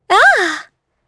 Isaiah-Vox_Happy4.wav